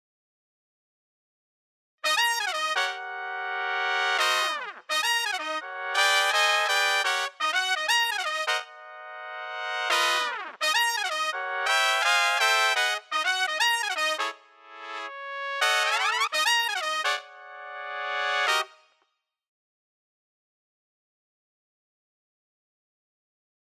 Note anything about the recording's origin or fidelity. Heres the same file with Straight Ahead Samples. All with Smart Delay, very slight stereo panning and very slight room reverb. Compressor and Limiter on the Master